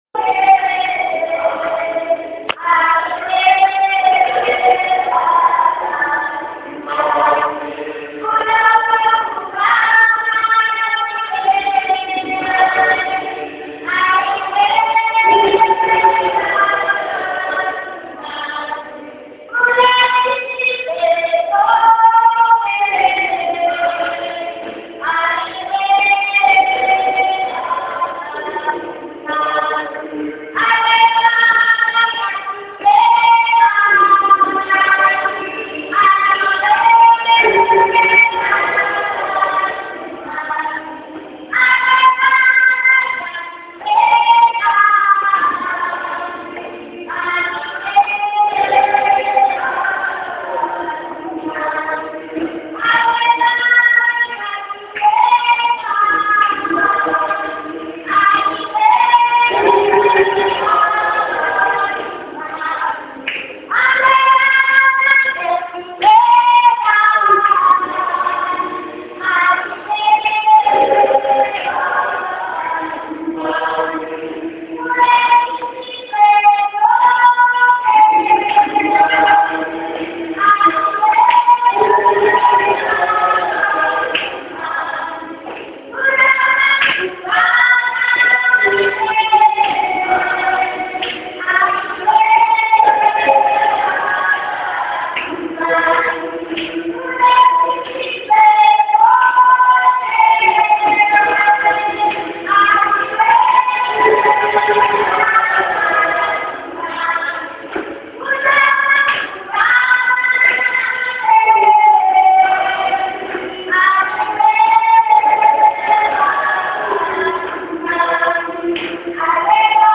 Thankfully it’s not just a rousing melody, but has the divine promise that the Father will hear us if we ask him for the Holy Spirit in the name of our Lord Jesus Christ.
Although it was not a full house the singing was quite strong – but listen here and convince yourself: